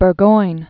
(bûr-goin, bûrgoin), John 1722-1792.